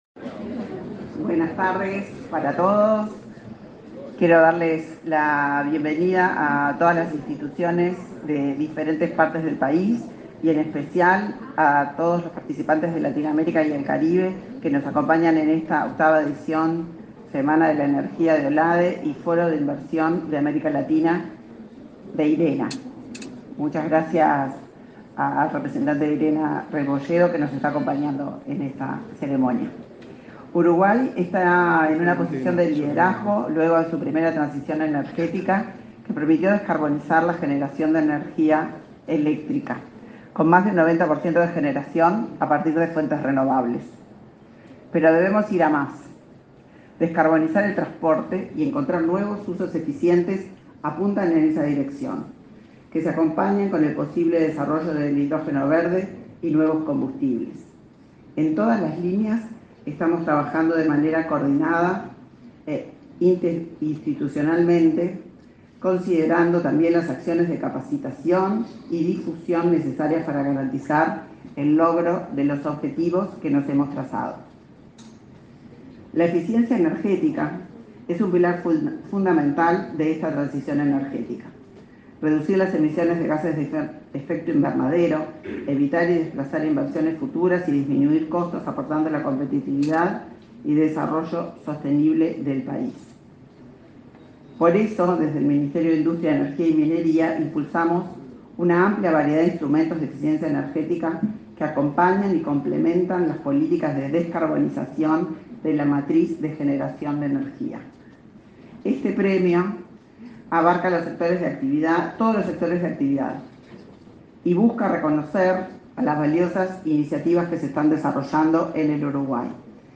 Acto de entrega del Premio a la Eficiencia Energética 2023